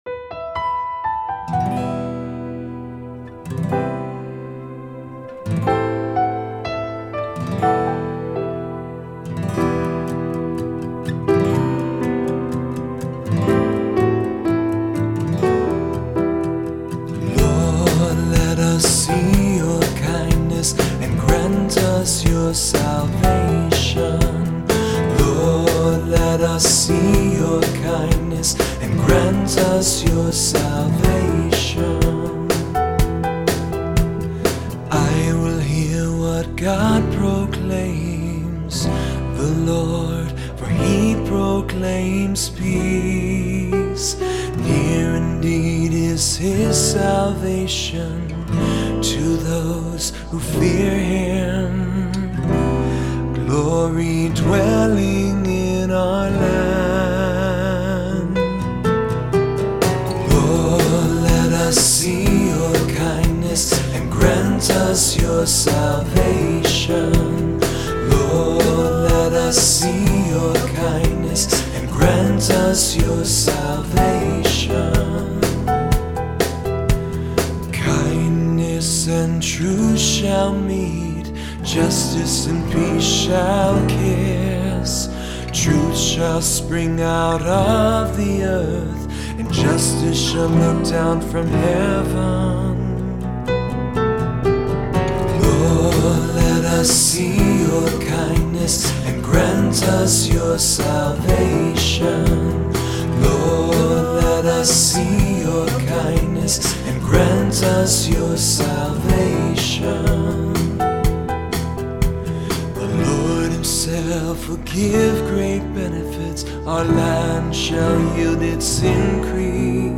Voicing: Two-part choir; Cantor; Assembly